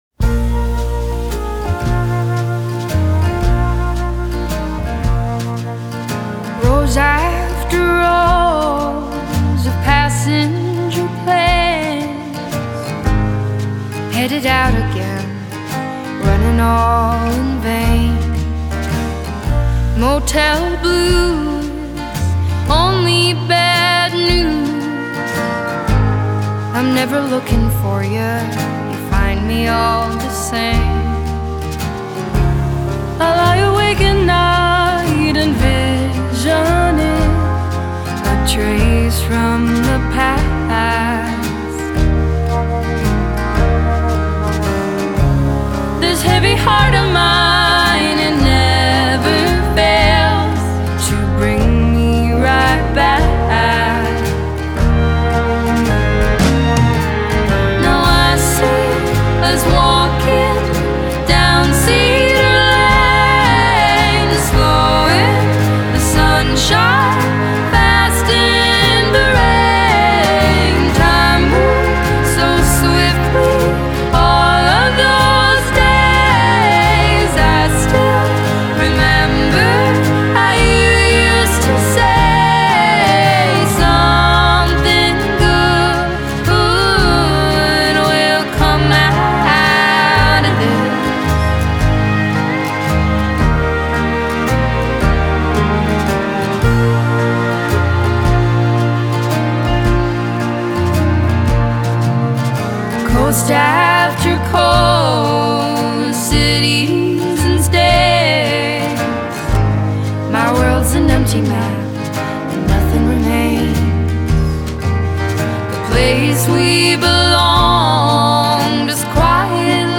The woozy delivery of the chorus is perfect